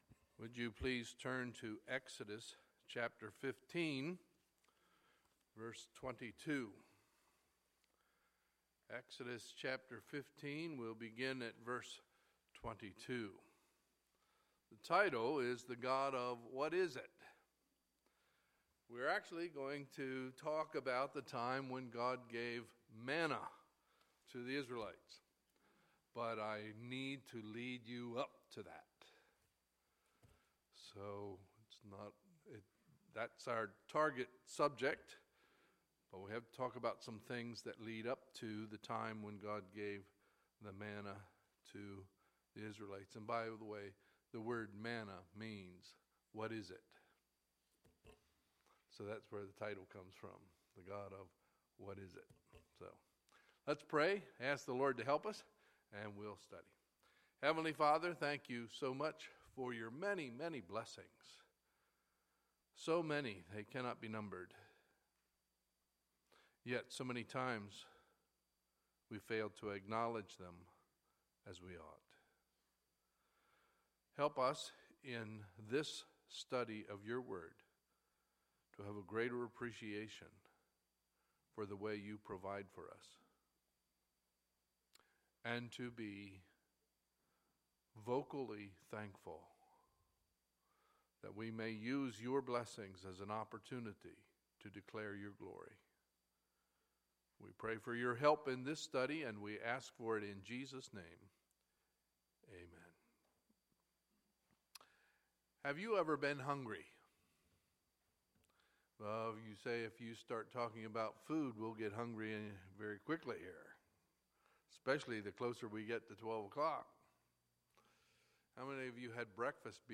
Sunday, June 26, 2016 – Sunday Morning Service